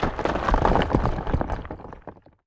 rock_smashable_falling_debris_04.wav